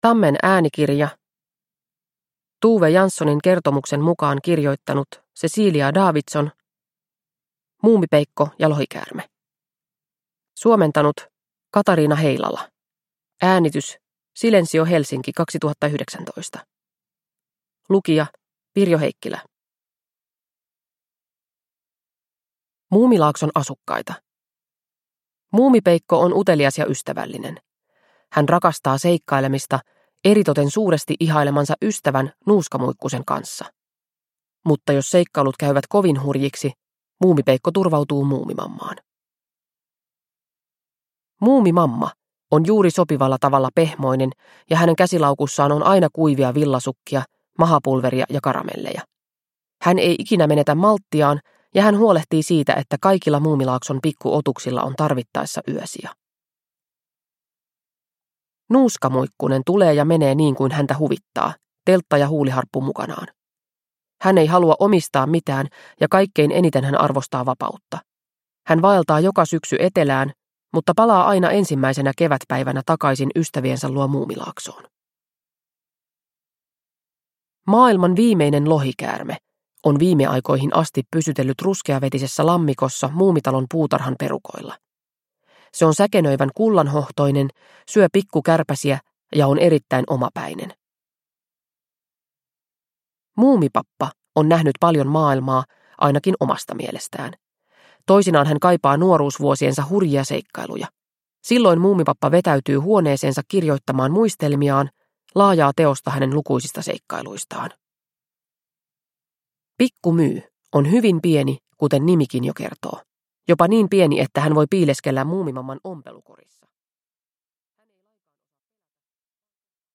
Muumipeikko ja lohikäärme – Ljudbok – Laddas ner